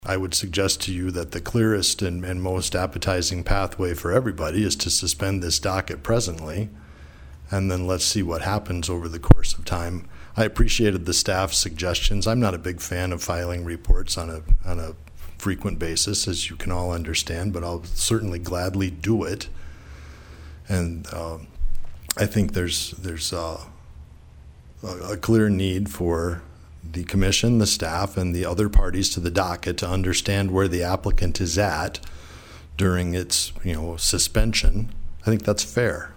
PIERRE, S.D.(HubCityRadio)- The South Dakota Public Utilities Commission held their meeting Thursday in Pierre.  One of the main topics was the status of the CO2 pipeline application made by Summit Carbon Solutions.